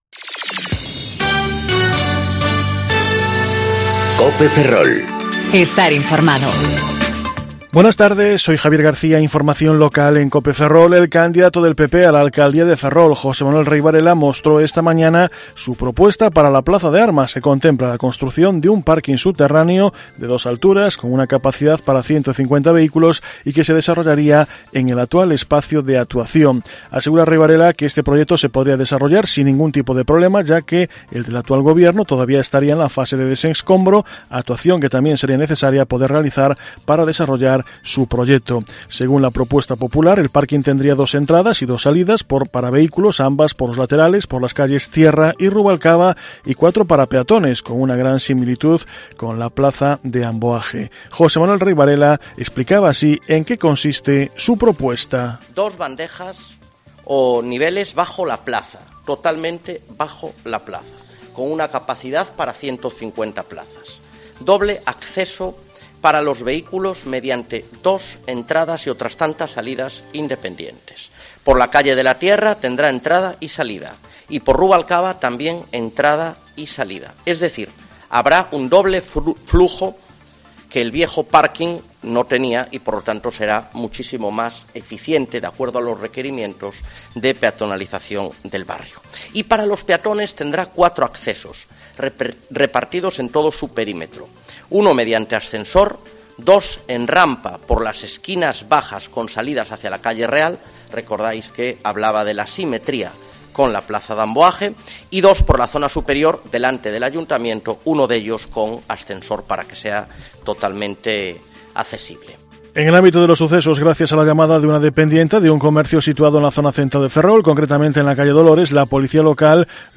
Informativo Mediodía Cope Ferrol - 3/05/2019 (De 14.20 a 14.30 horas)